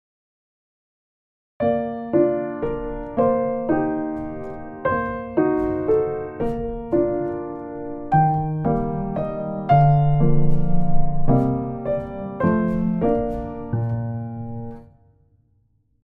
7級B/変ロ長調３拍子
３ 工夫して素敵なコードに